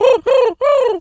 Animal_Impersonations
pigeon_call_sequence_02.wav